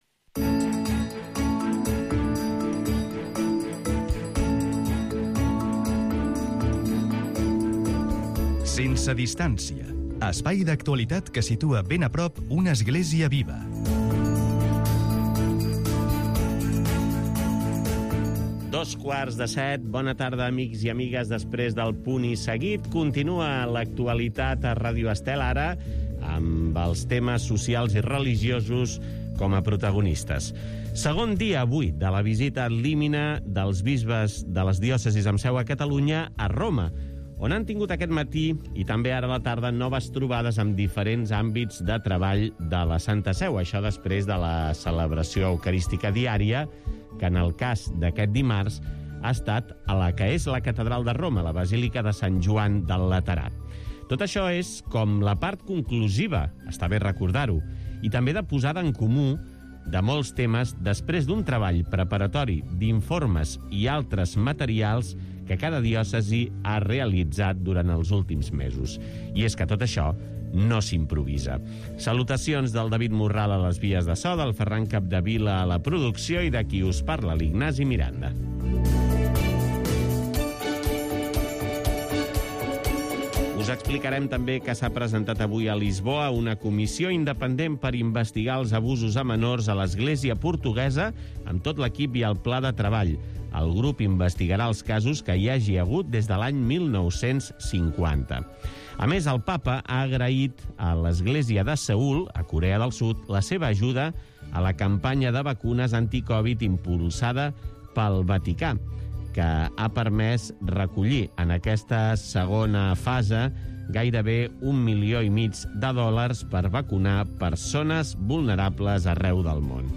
Un espai informatiu diari, sobre l'actualitat social i religiosa, amb vocació de proximitat. En 45 minuts, oferim una primera part amb les notícies més importants del dia sobre l'Església, les comunitats cristianes, les persones i entitats que ajuden els necessitats i tot allò que afecta el fet religiós i la solidaritat.